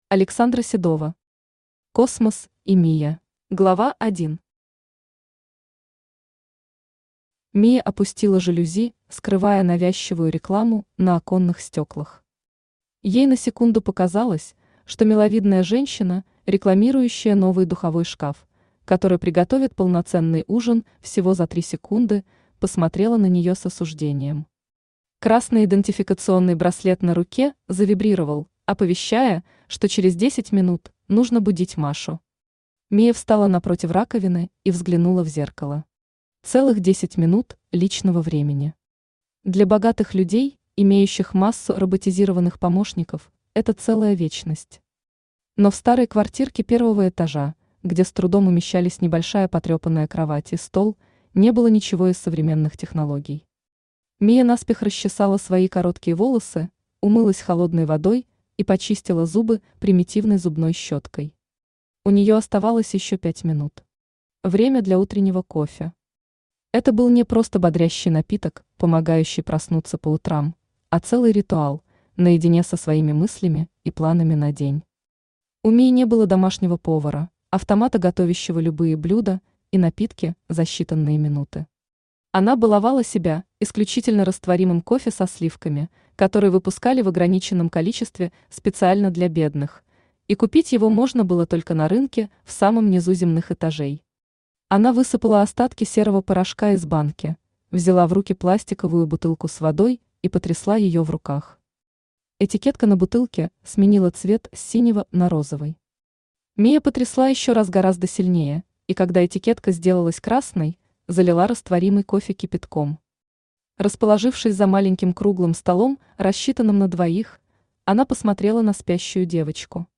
Аудиокнига Космос и Мия | Библиотека аудиокниг
Aудиокнига Космос и Мия Автор Александра Сергеевна Седова Читает аудиокнигу Авточтец ЛитРес.